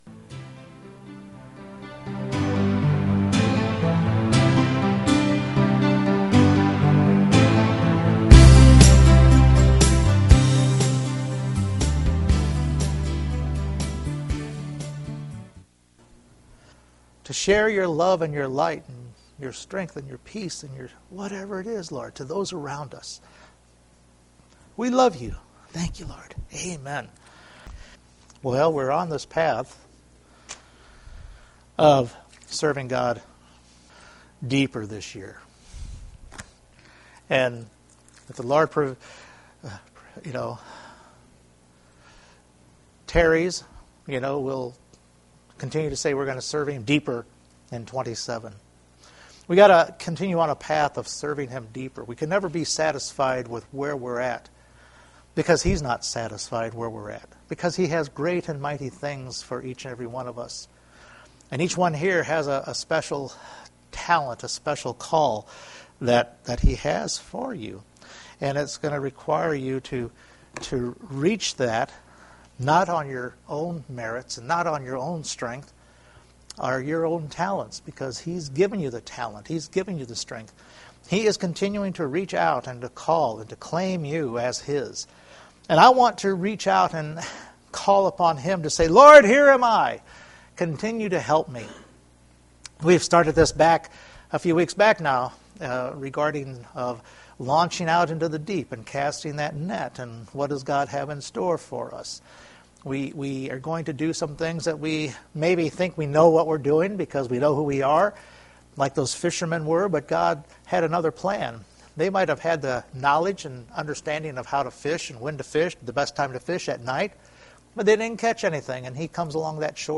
Luke 9:62 Service Type: Sunday Morning Jesus shares about he who puts his hand on the plow should not look back….